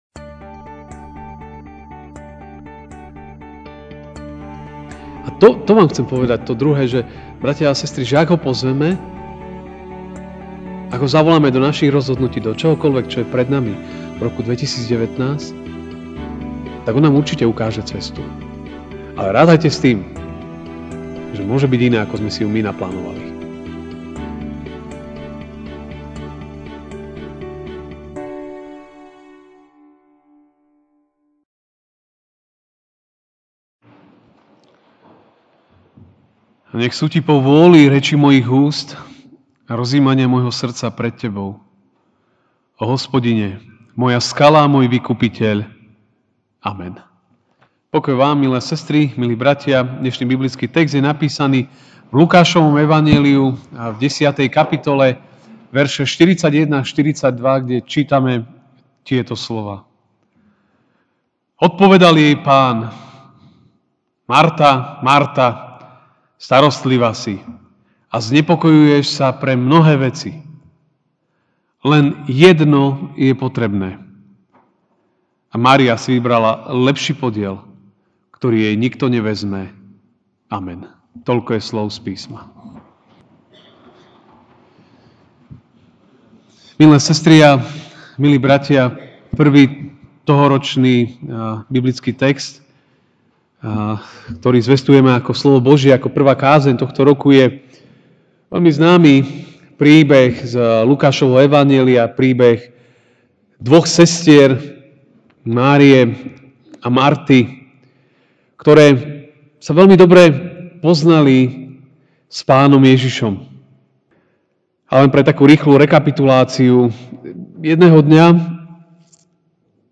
jan 01, 2019 Prítomnosť Pána Ježiša v našich životoch MP3 SUBSCRIBE on iTunes(Podcast) Notes Sermons in this Series Nový rok: Prítomnosť Pána Ježiša v našich životoch (L 10, 41-42) Odpovedal jej Pán: Marta, Marta, starostlivá si a znepokojuješ sa pre mnohé veci.